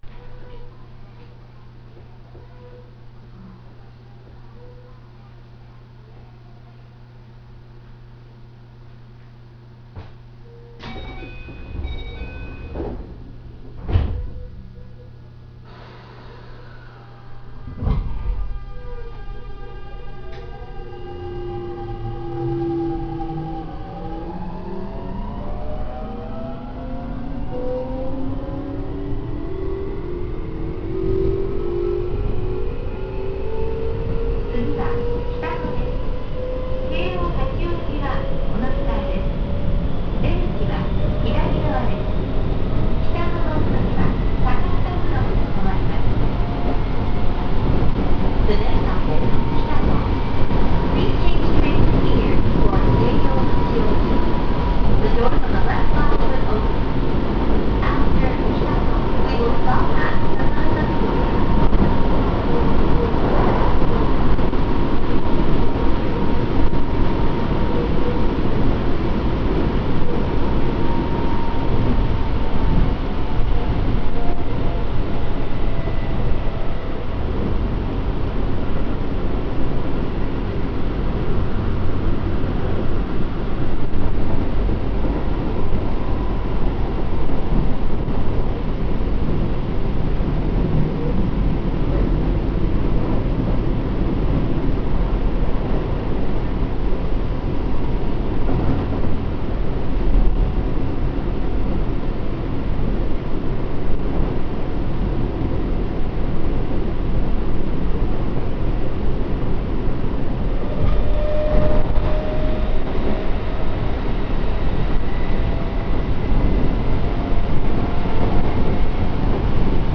・8000系走行音
【高尾線】めじろ台〜北野（4分34秒：1.45MB）
標準的な日立GTOで、とても重厚な音を出してくれます。近年、自動放送も設置されました。